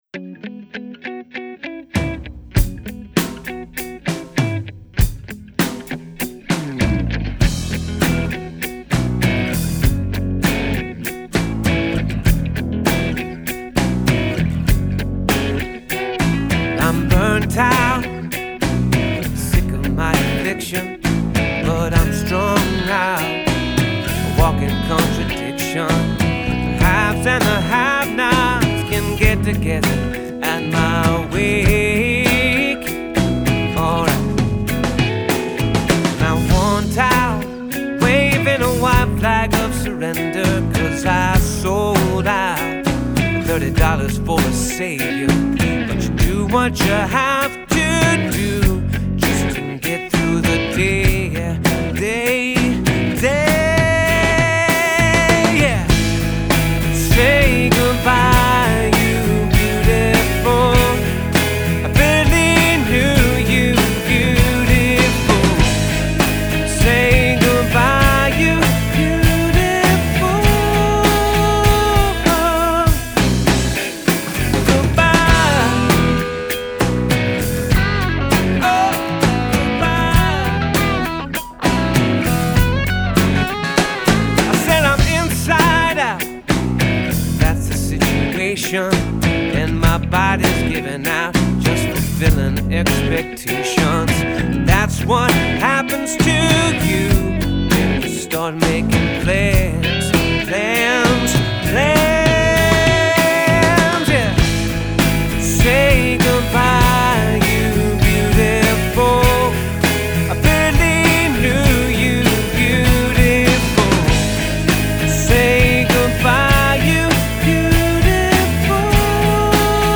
is a swaggering riff-led number